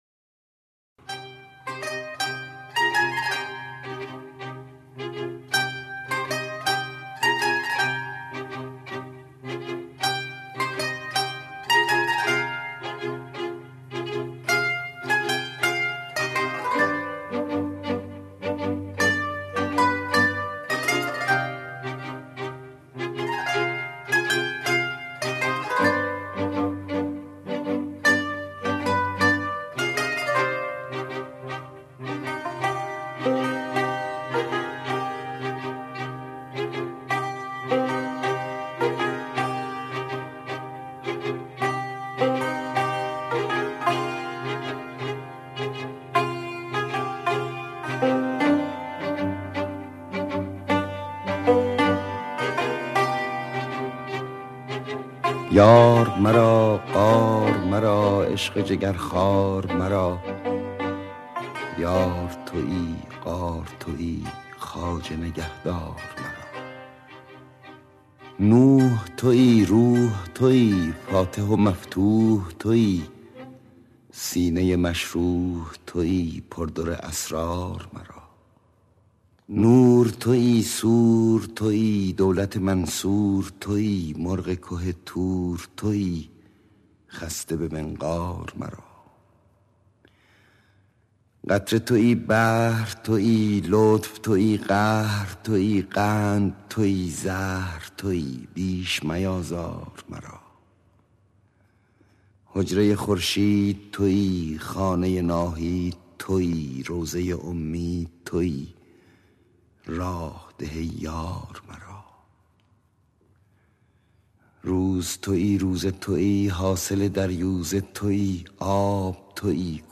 صدای: احمد شاملو
موسیقی متن:‌ فریدون شهبازیان
شعر خوانی احمد شاملو
شعرهای-مولوی-با-صدای-احمد-شاملو-راوی-حکایت-باقی-2.mp3